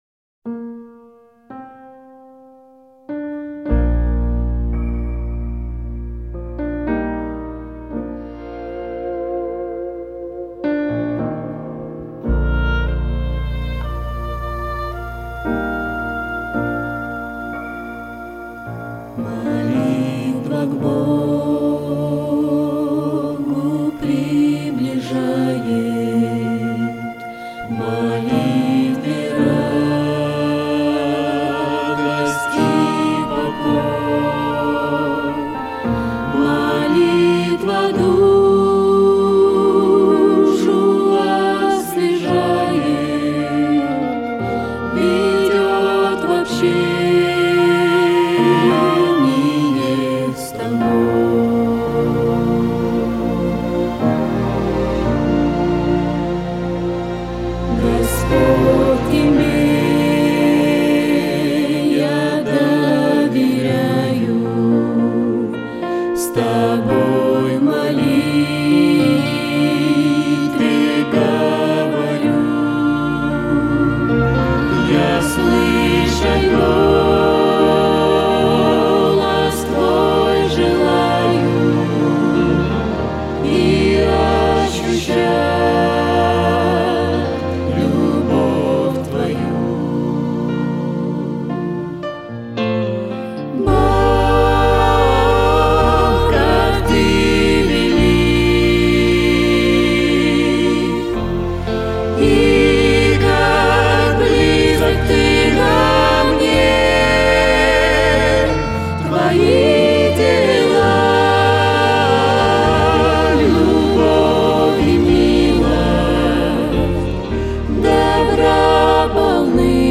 on 2015-12-27 - Молитвенное пение